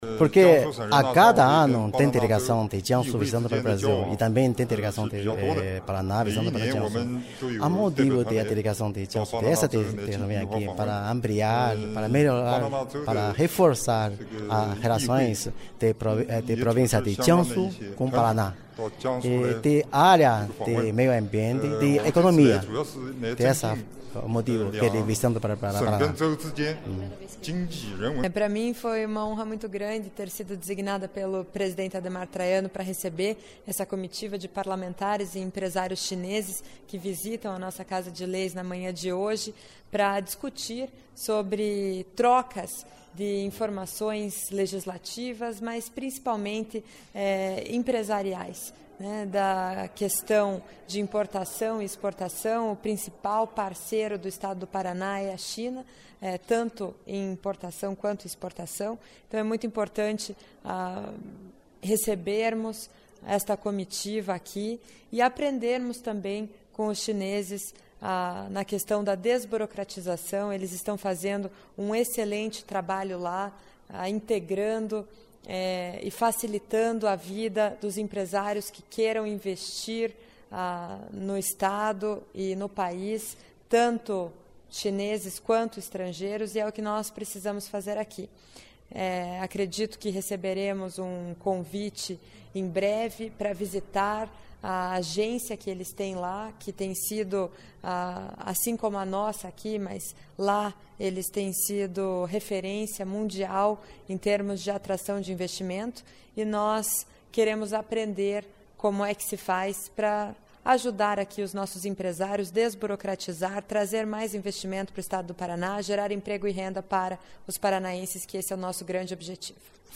A visita da comitiva foi na manhã desta quinta-feira (17) e aconteceu no Salão Nobre da Assembleia Legislativa. Ouça as entrevistas com o parlamentar Wang Li, secretário-geral do Congresso Popular da Província de Jiangsu; em seguida, com a deputada Maria Victória (PP) , que preside a Comissão do Mer...